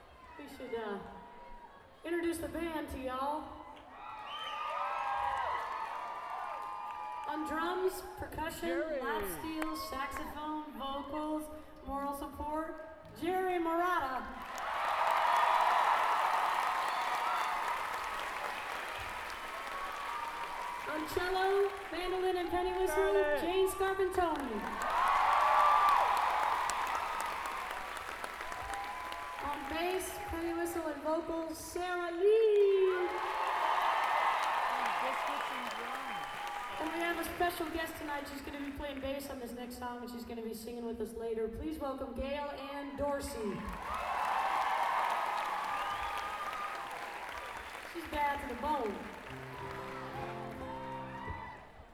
lifeblood: bootlegs: 1995-04-21: sports and rec center - saratoga springs, new york
08. band introductions (0:51)